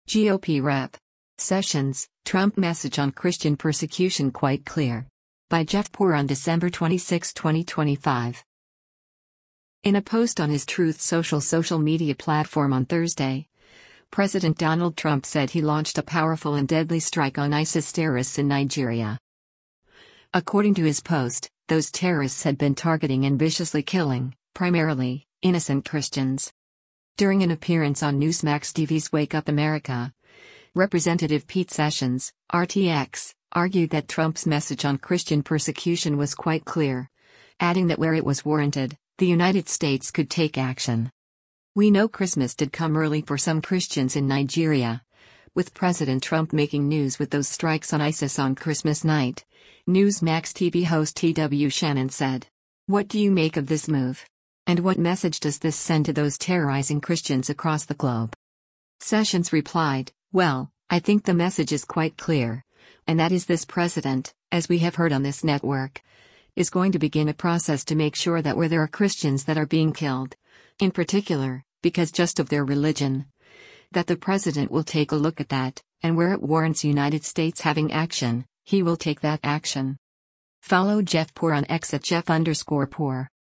During an appearance on Newsmax TV’s “Wake Up America,” Rep. Pete Sessions (R-TX) argued that Trump’s message on Christian persecution was “quite clear,” adding that where it was warranted, the United States could take action.